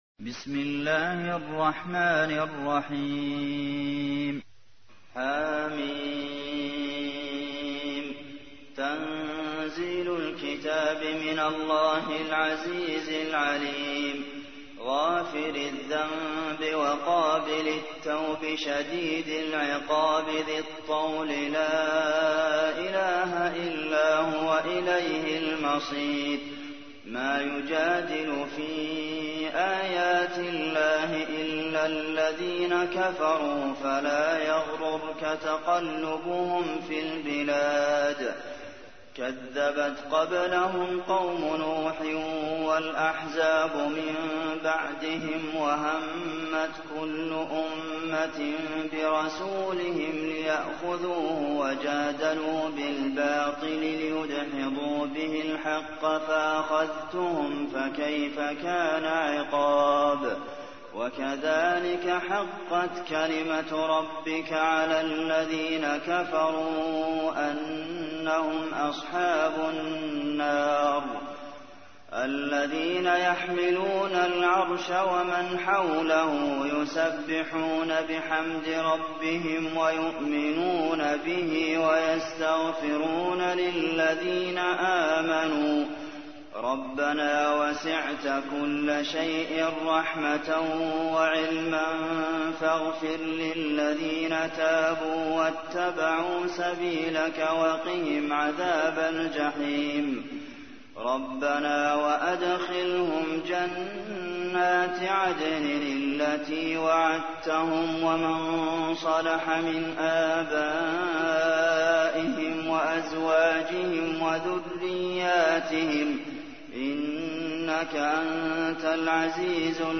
تحميل : 40. سورة غافر / القارئ عبد المحسن قاسم / القرآن الكريم / موقع يا حسين